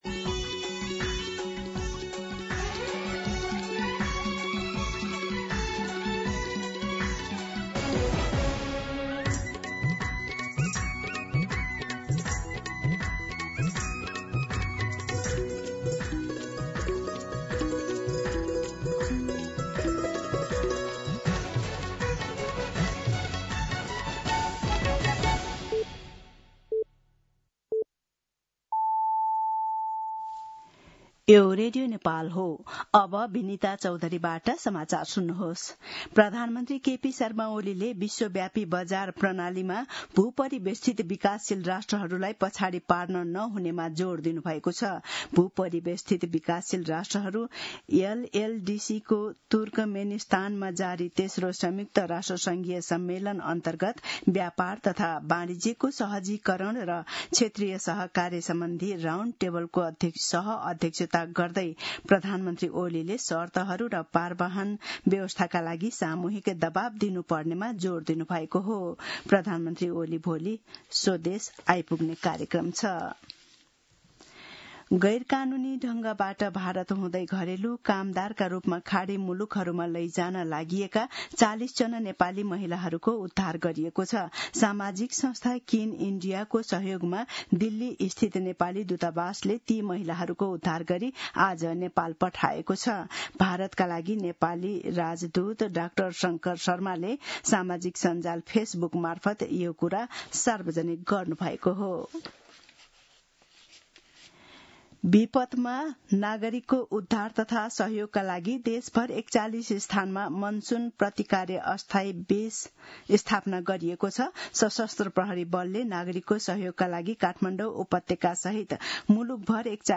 मध्यान्ह १२ बजेको नेपाली समाचार : २२ साउन , २०८२
12-pm-Nepali-News-2.mp3